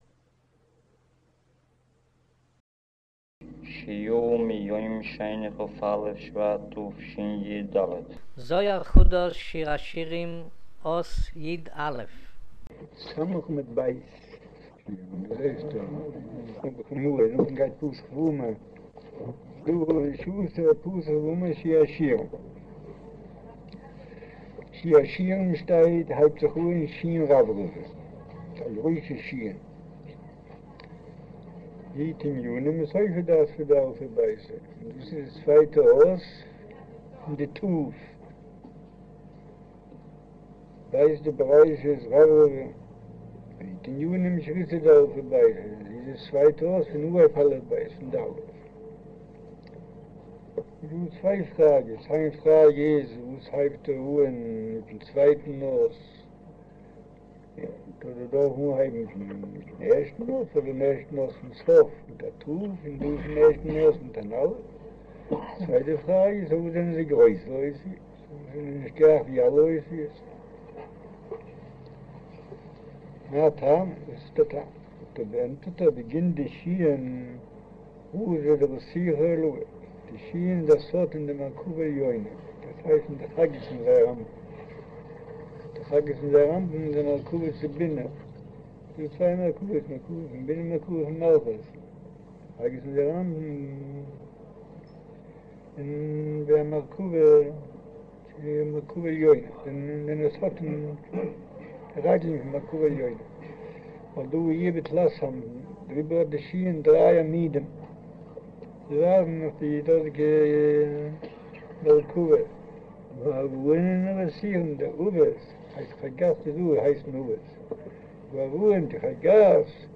אודיו - שיעור מבעל הסולם זהר חדש שיר השירים אות יא' - יט'